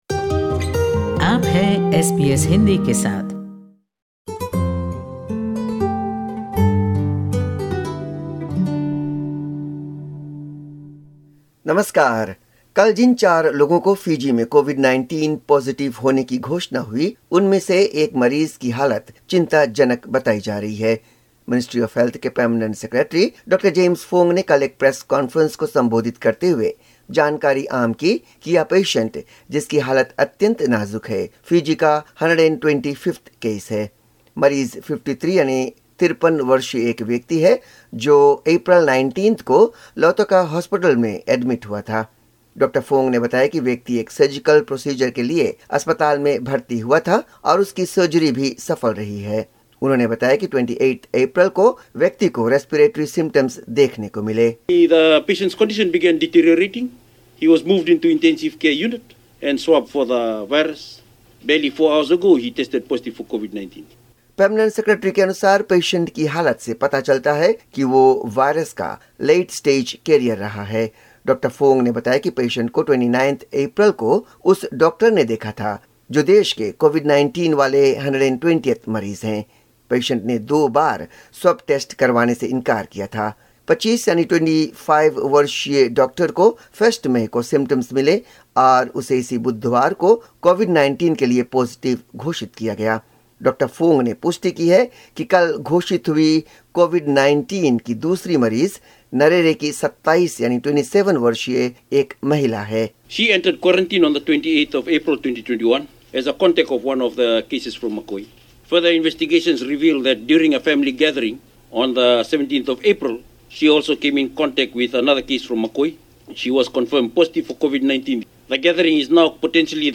Catch the latest Fiji news report of 6/05/2021.